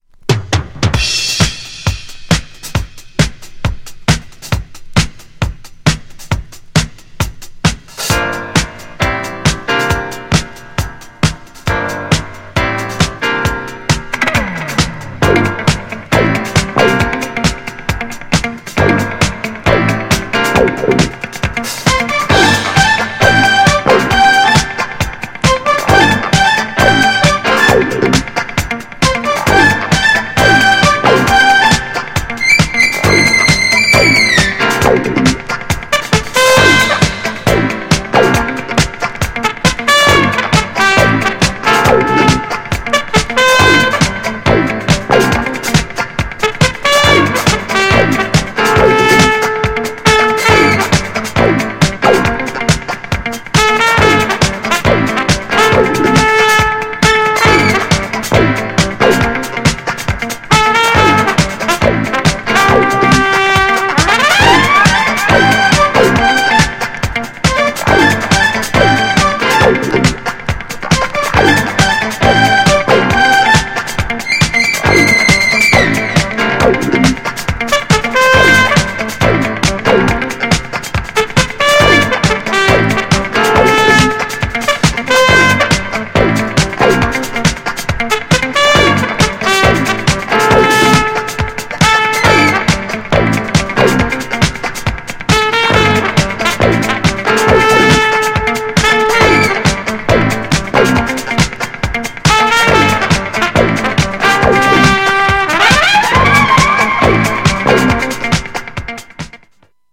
ホーンがフロアに鳴り響くINSTRUMENTAL!! 軽やかでダンサブルなFUSIONダンスのA面とスペーシーなB面!!
GENRE Dance Classic
BPM 126〜130BPM
GARAGE_CLASSIC
JAZZY
トランペット # 空間的